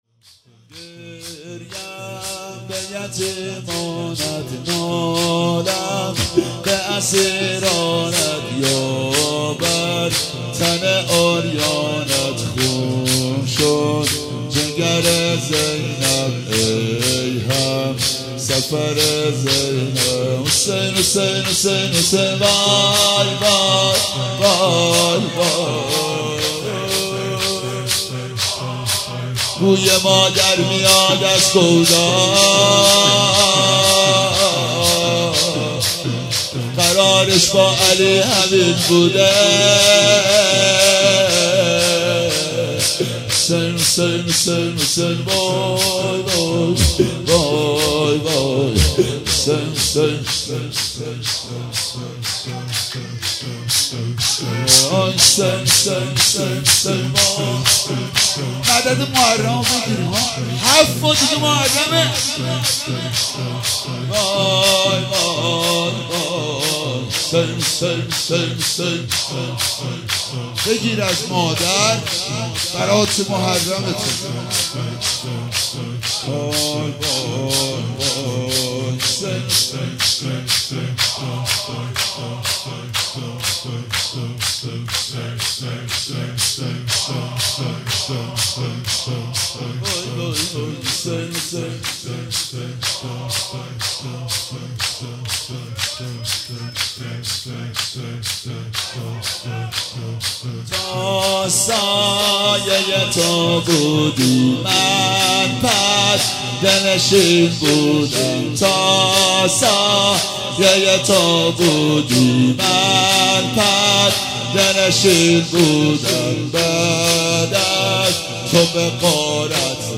ایام فاطمیه 95
شور - گریم به یتیمانت